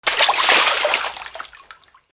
/ M｜他分類 / L30 ｜水音-その他
水面を叩く 強 04